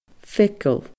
feagal /fegaL/